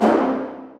hit 1.wav